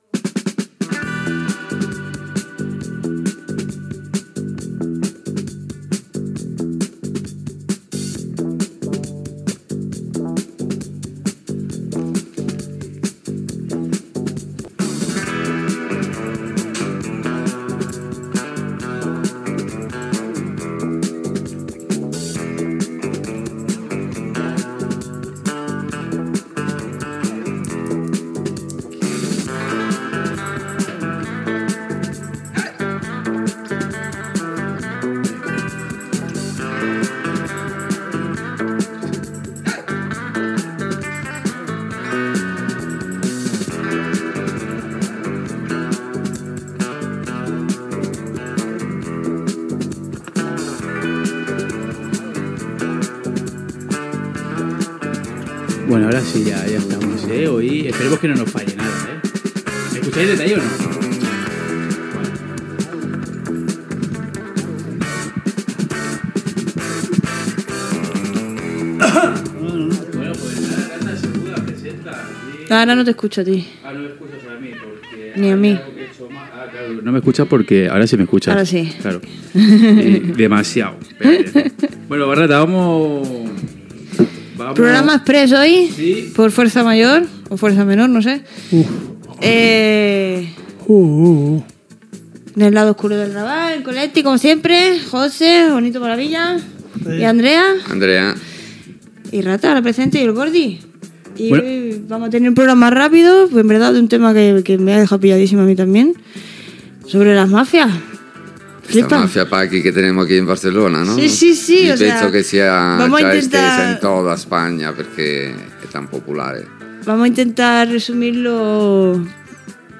1ebabb963b2e8b603d8e6f3ed7ccdda1b19356d5.mp3 Títol Ravalmedia Emissora Ravalmedia Titularitat Tercer sector Tercer sector Barri o districte Nom programa El lado oscuro del Raval Descripció Inici del programa, presentació de les persones que hi intervenen. Debat sobre la màfia paquistanesa i la sol·licitud de cites policials per fer tràmits de documentació d'estrangeria
El programa es produia a l'estudi de ràdio de la cooperativa Colectic del Raval de Barcelona.